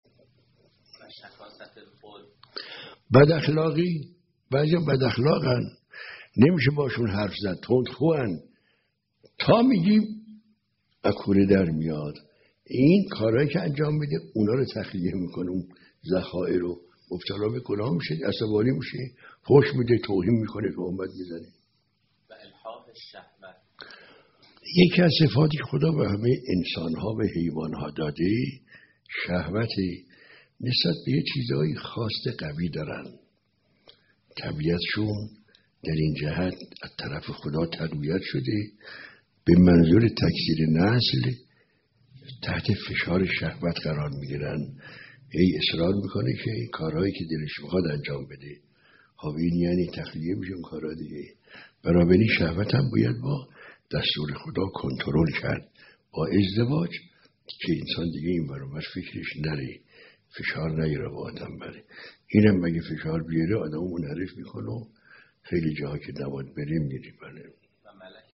به گزارش خبرگزاری حوزه، مرحوم آیت الله عزیزالله خوشوقت از اساتید اخلاق حوزه در یکی از دروس اخلاق خود به موضوع «بداخلاقی و کنترل شهوت» پرداختند که متن آن بدین شرح است: